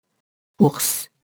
ours [urs]